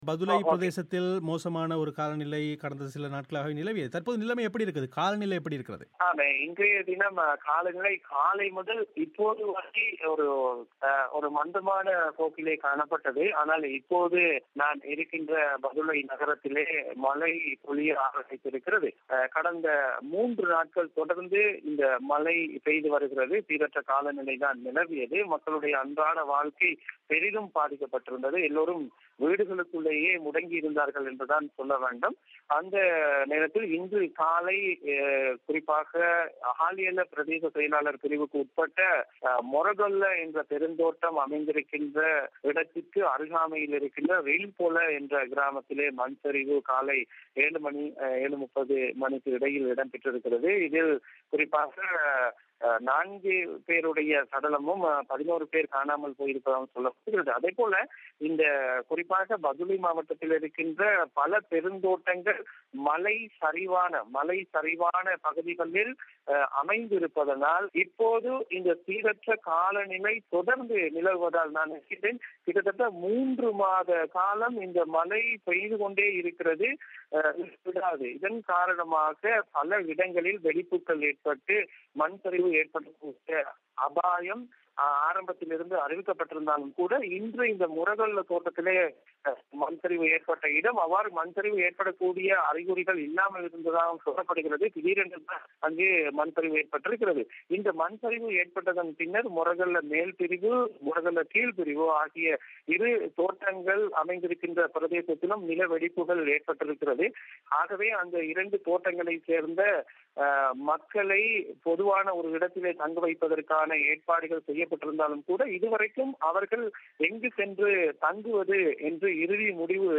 பிபிசி தமிழோசைக்கு அளித்த செவ்வி